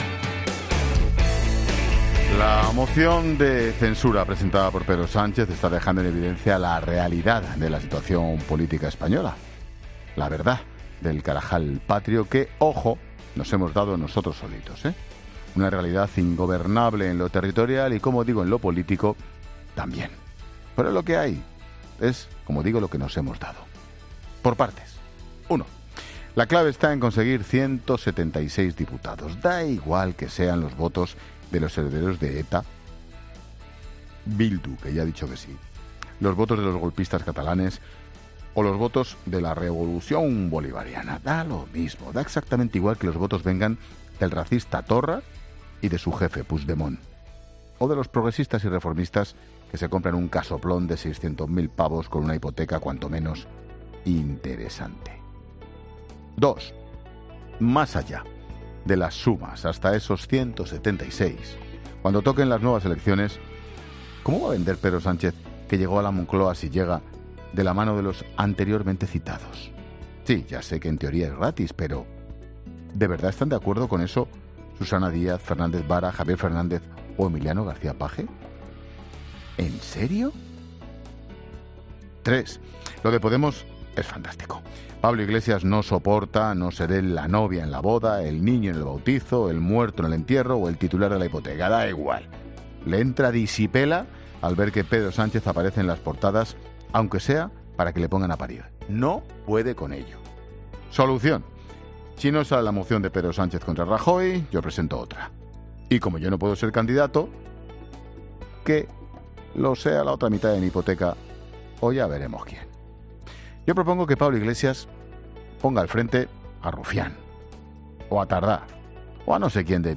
Monólogo de Expósito
El comentario de Ángel Expósito sobre la moción de censura que se debate en el Congreso este jueves.